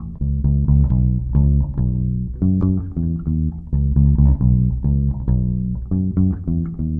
Drum loops » dub disco 2
描述：Heavy disco dub loop with light swing.
标签： disco bass dub
声道立体声